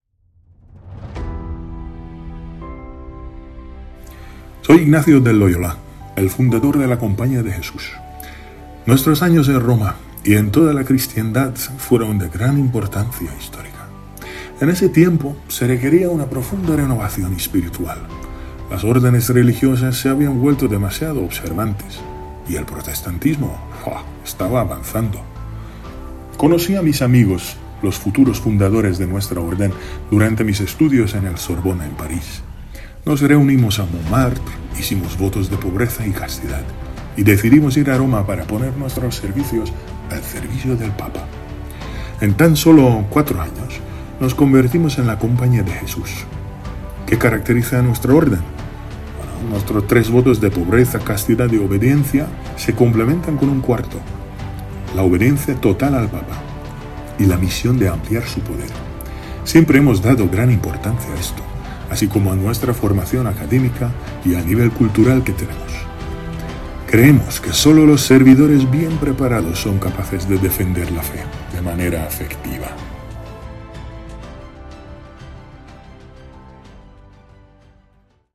Ignacio de Loyola explica su vida y la fundación del orden jesuita